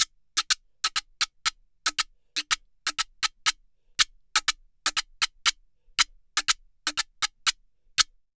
Guiro_Salsa 120_2.wav